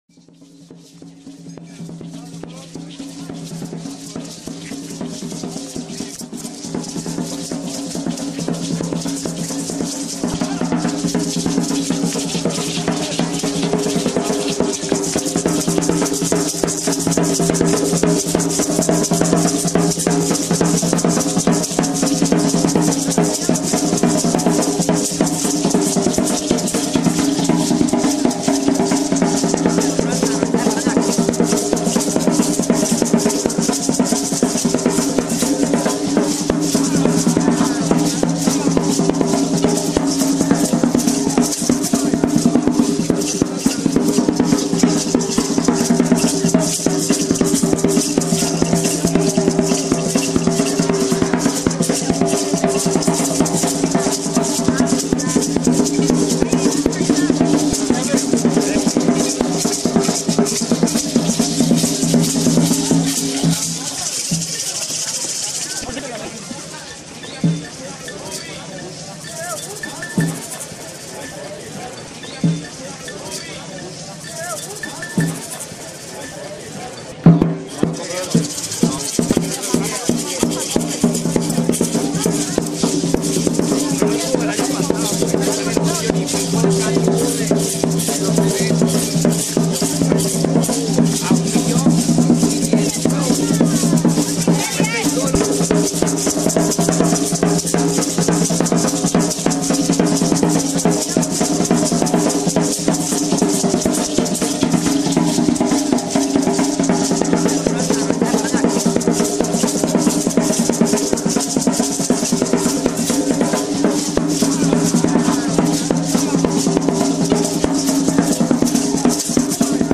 La dinámica central de la danza representa la sumisión del mal. Los promeseros bailan por las calles al ritmo de la caja (tambor), maracas y otros instrumentos, pero al encontrarse frente al Santísimo Sacramento, las máscaras deben bajar hacia el piso y los danzantes se arrodillan en señal de rendición.
La música tradicional que acompaña el recorrido consta de toques de tambor que dictan cuatro pasos fundamentales: el corrío, el escobillao, el reposo y la bamba. 5)